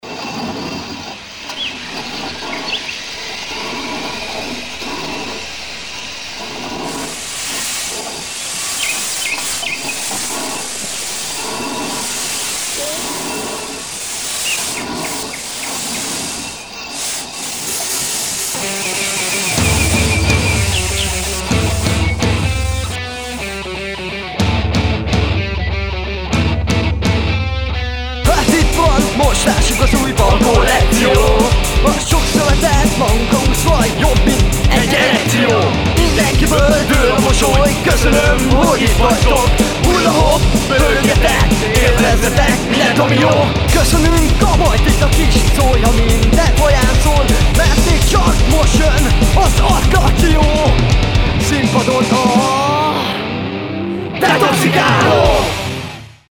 ének+hörrr!
gityó+lalala
basszer+lala